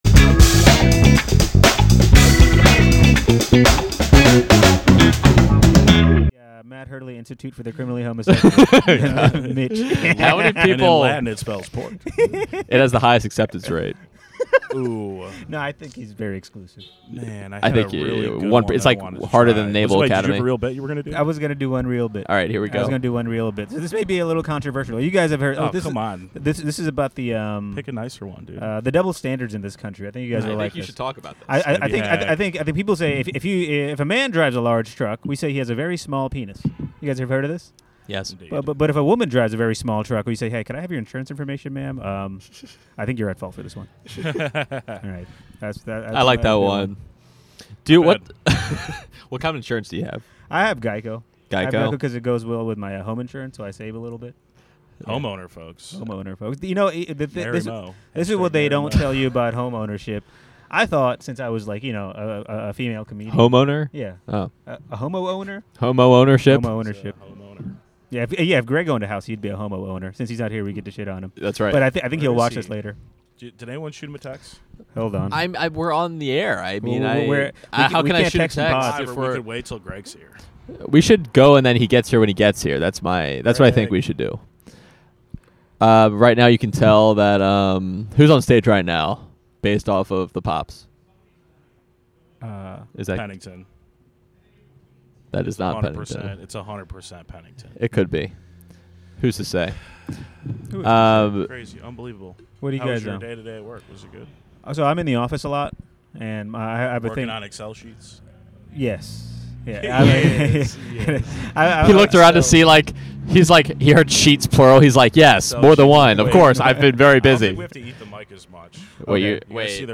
Stand-Up Contact email
Recorded 9/8/25 at a monday night show in canton.